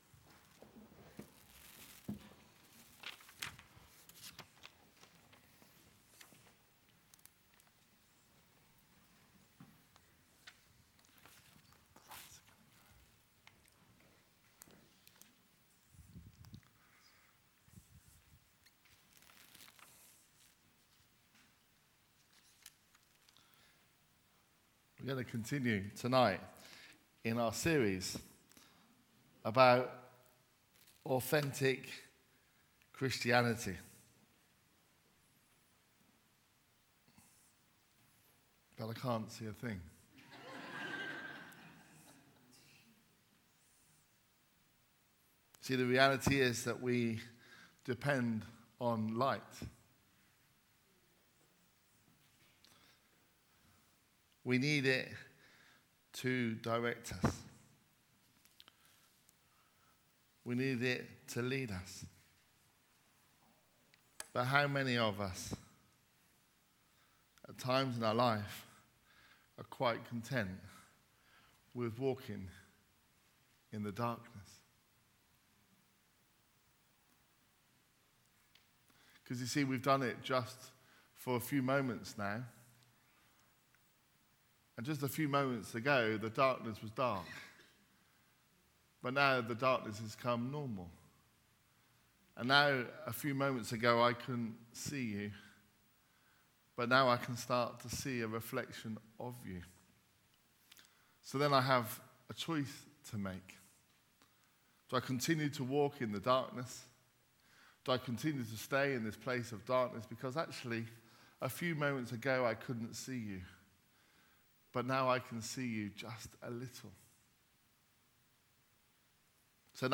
A message from the series "Authentic Christianity."